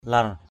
/lar/ 1. (d.) cuộn để quấn tấm lụa. 2.